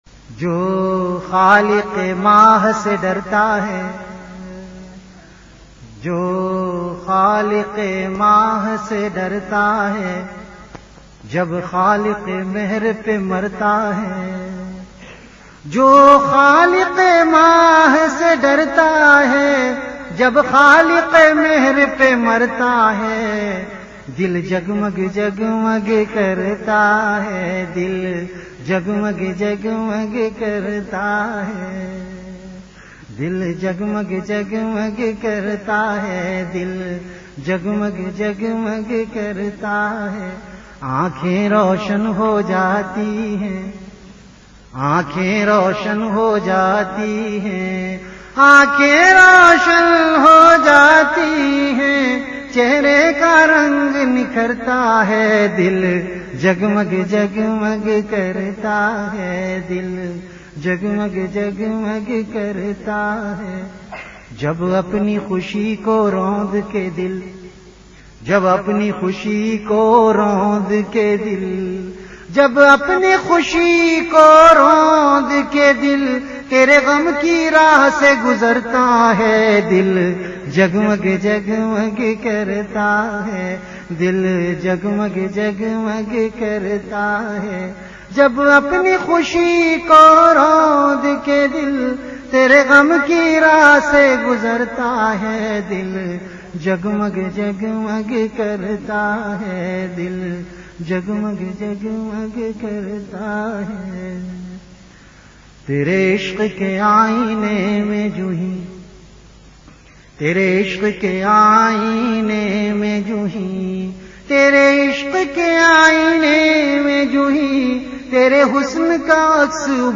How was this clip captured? VenueKhanqah Imdadia Ashrafia Event / TimeAfter Isha Prayer